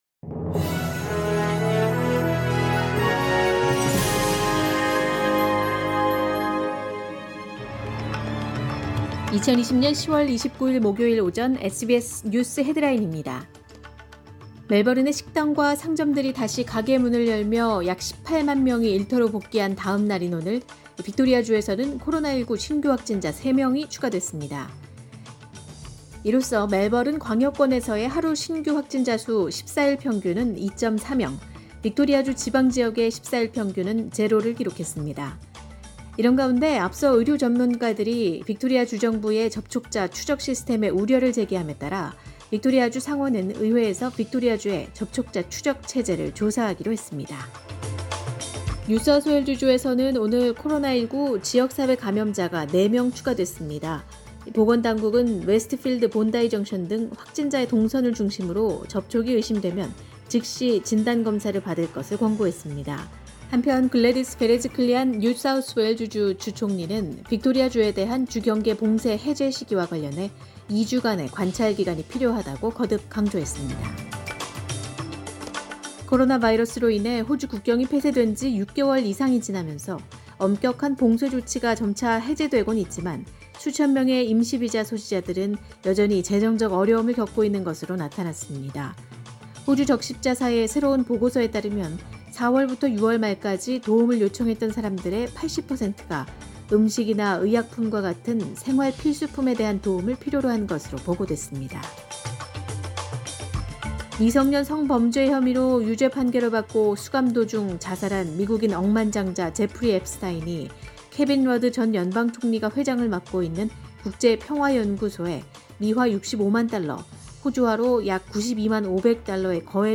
2020년 10월 29일 목요일 오전의 SBS 뉴스 헤드라인입니다